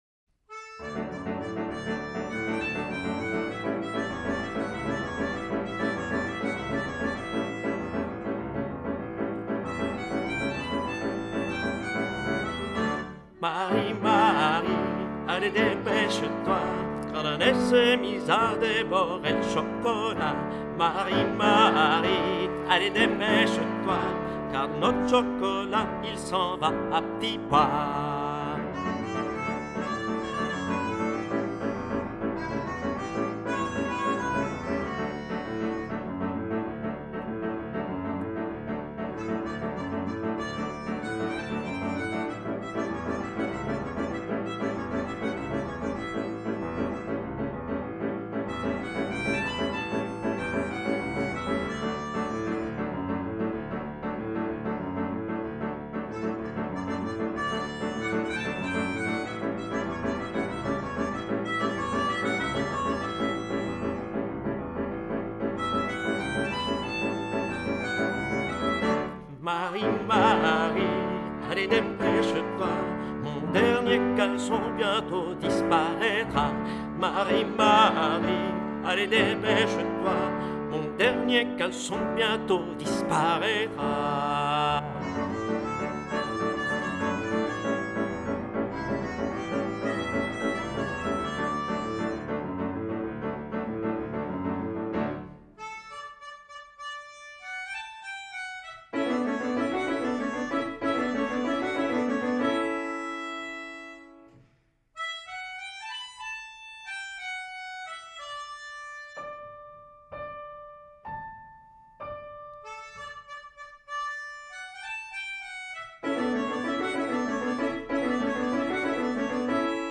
Genre :  ChansonComptine
Style :  Avec accompagnement
Une chanson à l'atmosphère chaleureuse et narrative, parfaite pour la période hivernale !
Enregistrement ténor
Hacia Belelen - Tenor.mp3